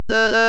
17.4 Artikulatoorne süntees Praatis
Sellegipoolest proovime ka artikulatoorse sünteesiga ühe katsetuse läbi teha ja sünteesime sõna tere.
Create Speaker: "Maret", "female", "2"
Vokaalid on võrdlemisi redutseeritud, kuid sõna on äratuntav.